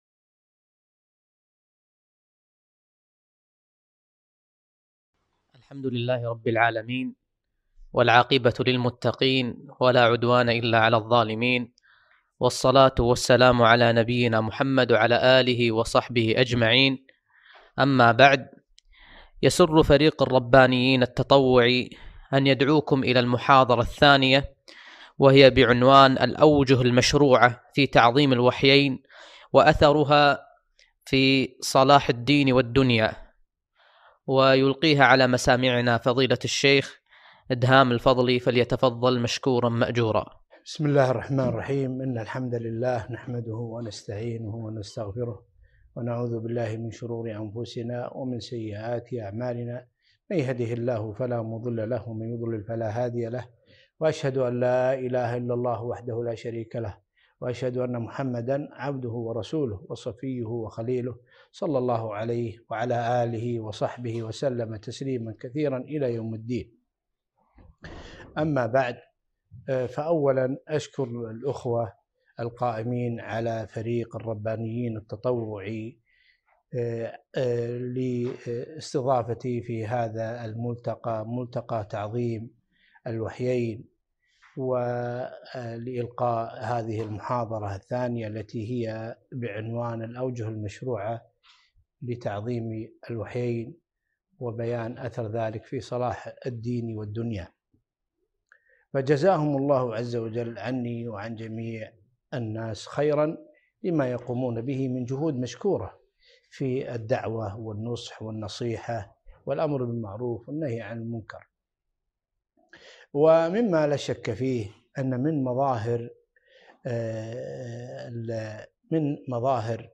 محاضرة - الأوجه المشروعه في تعظيم الوحيين